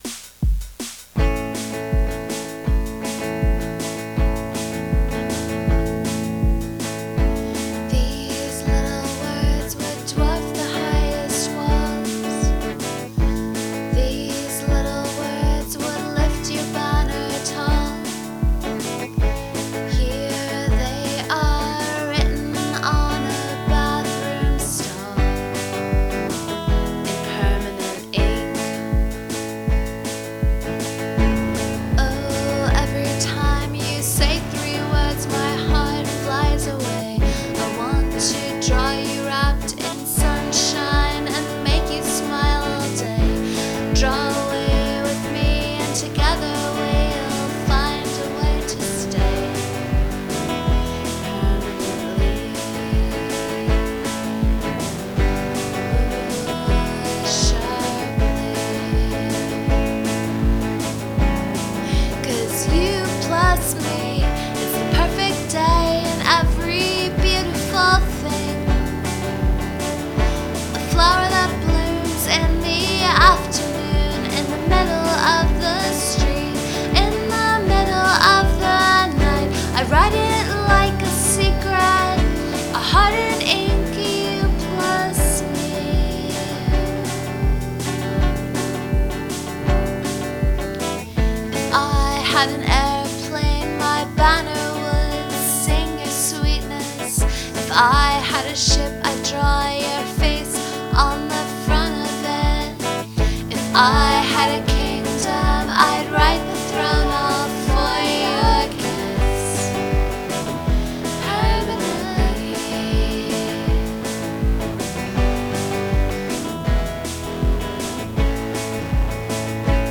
verse verse chorus verse chorus chorus
but this is obviously a cure song. i mean, if you speed it up a tiny bit and have boris williams play drums on it, and then it's pretty much already on "wish". these harmonies completely make my day. yow.
yay for happy love songs, so hard to write for some reason!